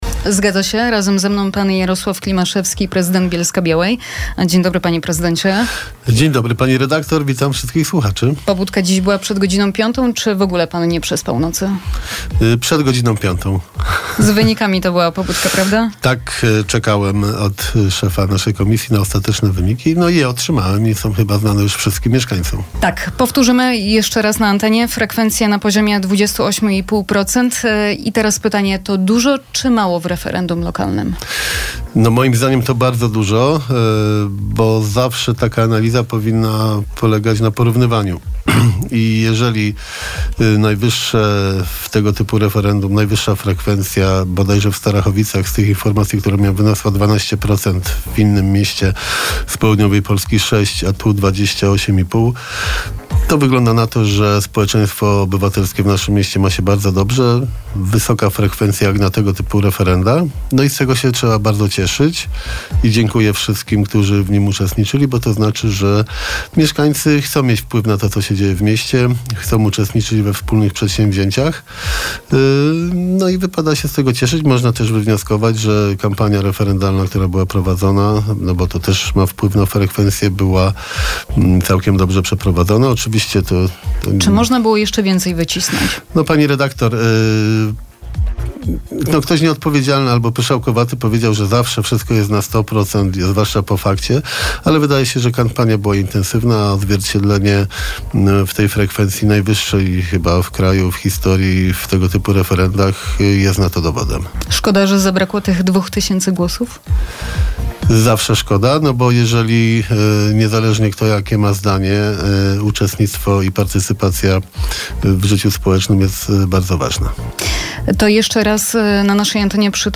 O decyzje, w zakresie kwestii z referendum, pytaliśmy dzisiaj na naszej antenie prezydenta miasta (cała rozmowa do odsłuchania